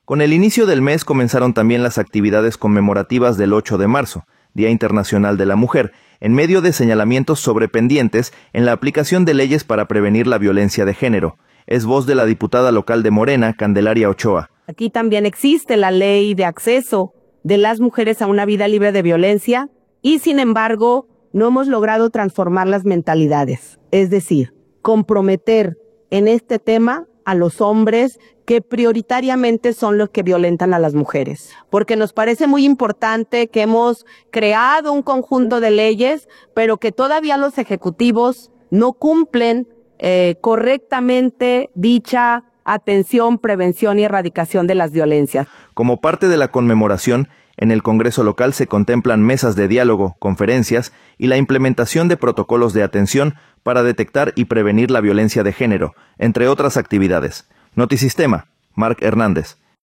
Con el inicio del mes comenzaron también las actividades conmemorativas del 8 de marzo, Día Internacional de la Mujer, en medio de señalamientos sobre pendientes en la aplicación de leyes para prevenir la violencia de género. Es voz de la diputada local de Morena, Candelaria Ochoa.